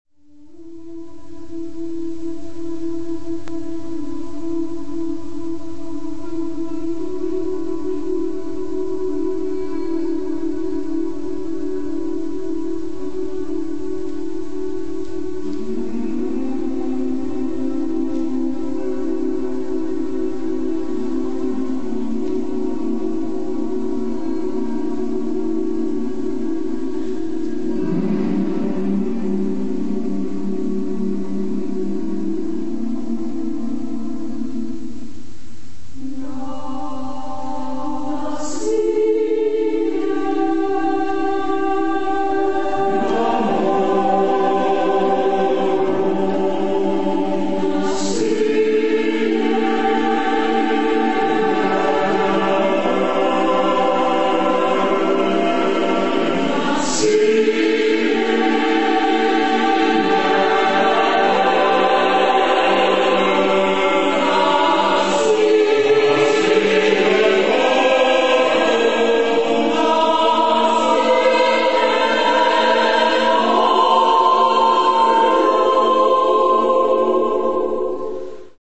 Carácter de la pieza : jovial
Tipo de formación coral: SSAATTBB  (8 voces Coro mixto )
Tonalidad : re (centro tonal)